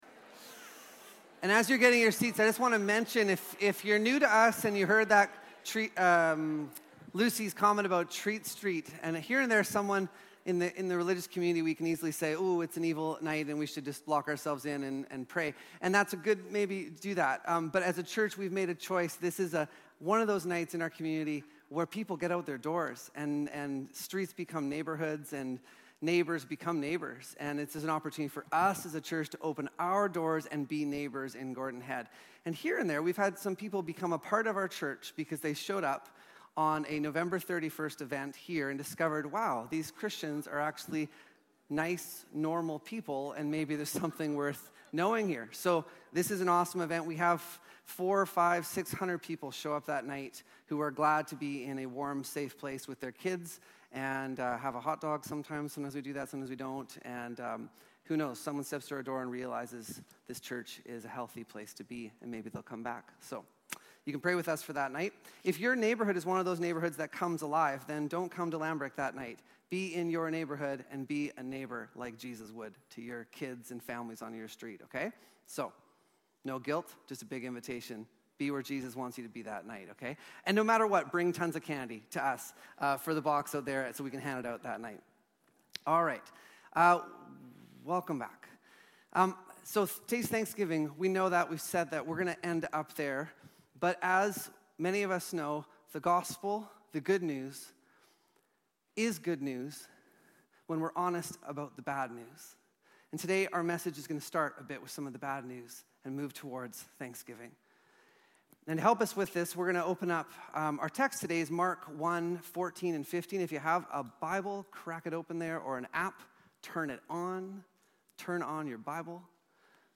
Sermons | Lambrick Park Church